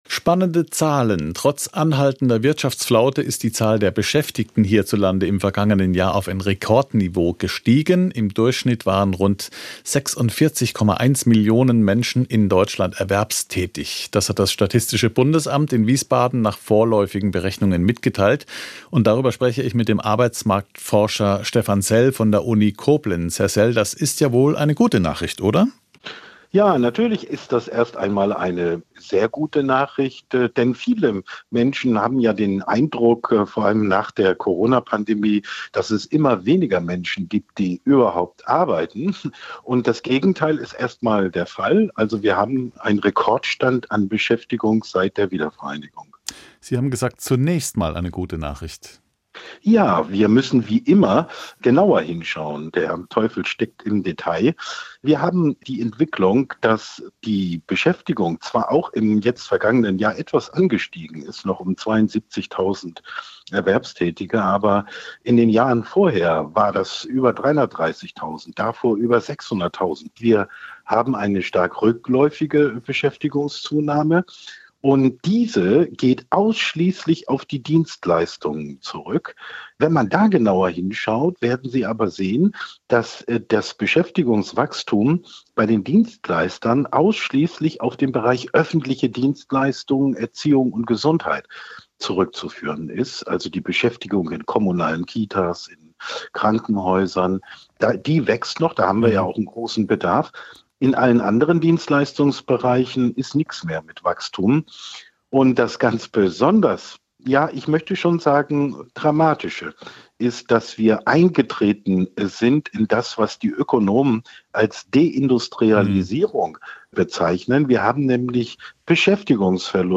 "SWR Aktuell Im Gespräch" - das sind Interviews mit Menschen, die etwas zu sagen haben.